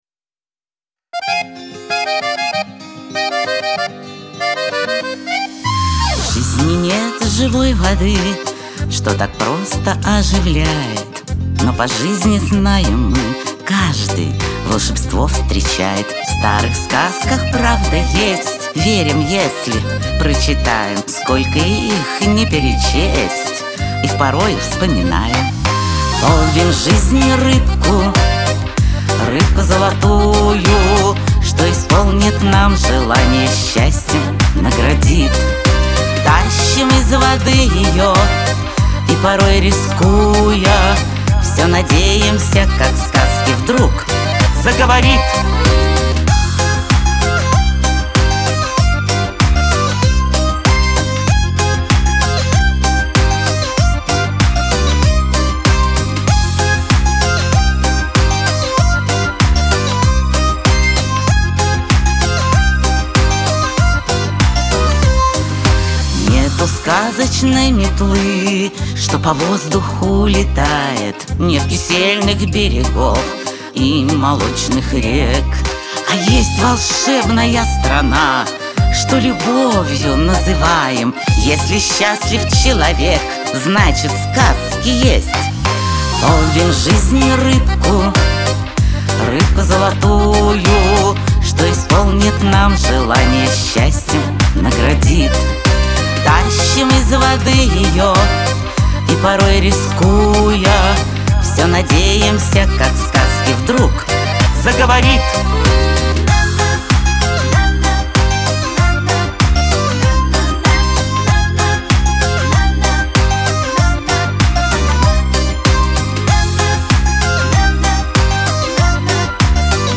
Лирика , диско